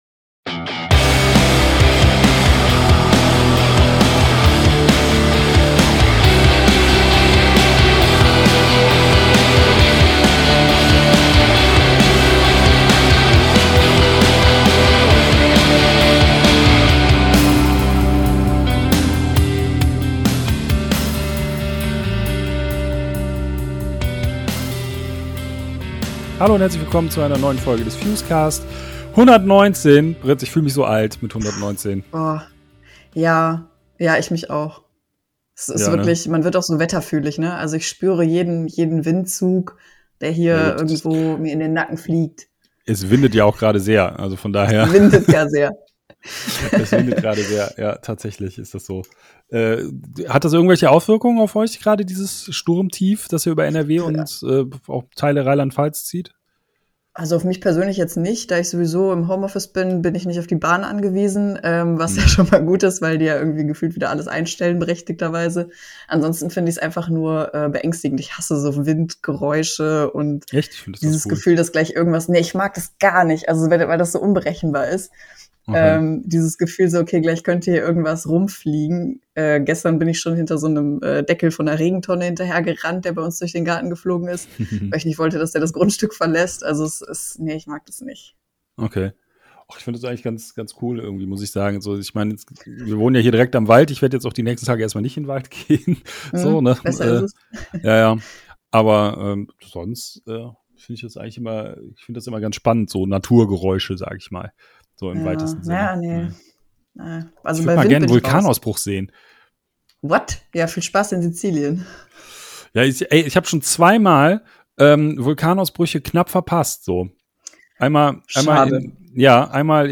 Außerdem bei uns im Interview: ZEAL AND ARDOR!